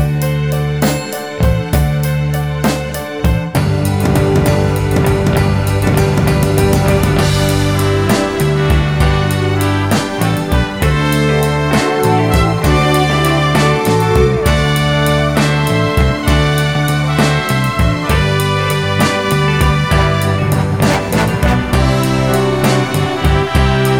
No Backing Vocals Crooners 3:08 Buy £1.50